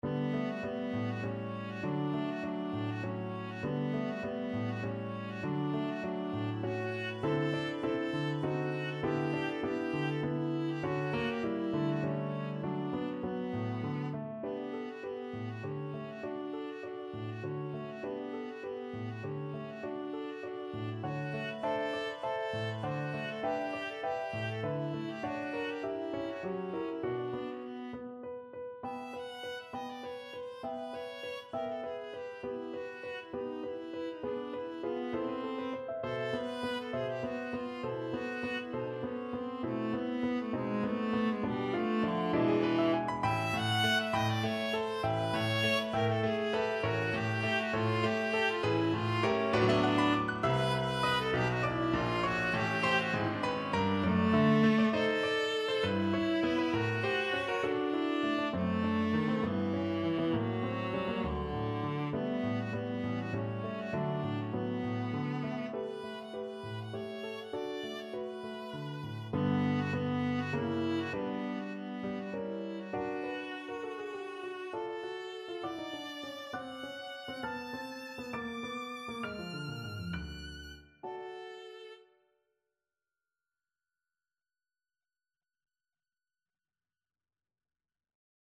Viola version
3/4 (View more 3/4 Music)
D4-F#6
Allegro giusto =200 (View more music marked Allegro)
Classical (View more Classical Viola Music)